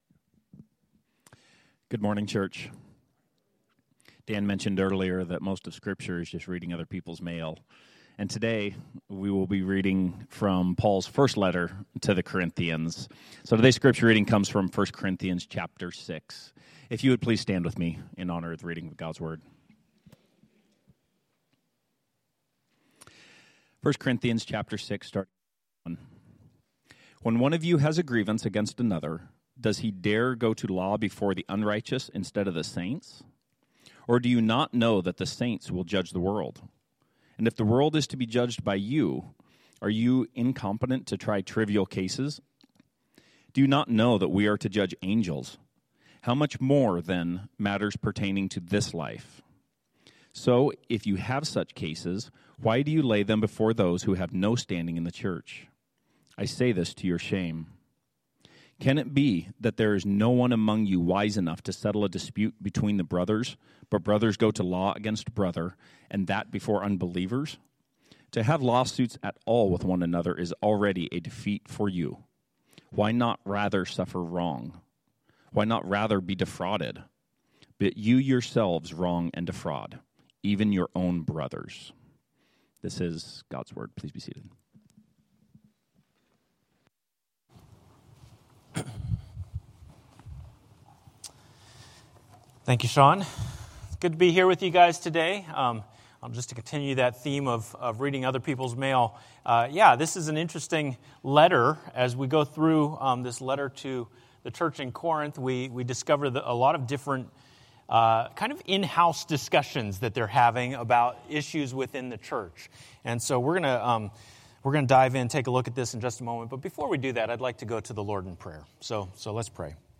A message from the series "Repentance."